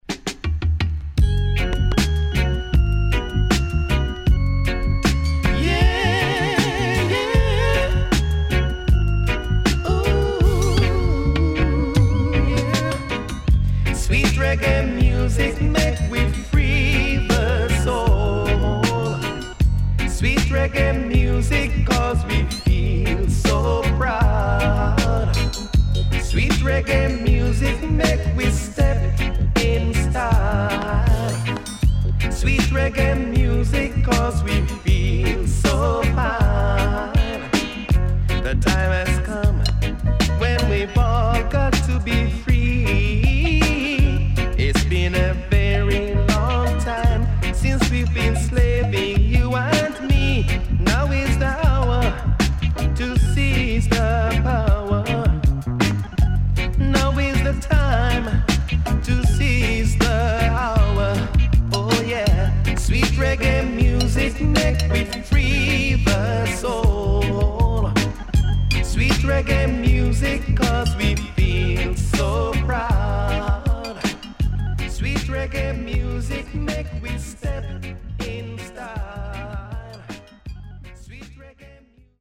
83年マイナー調からGood Mediumまで良曲多数